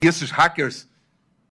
Play, download and share gilmar mendes dizendo RRAQUERSS original sound button!!!!
gilmar-mendes-dizendo-rraquerss.mp3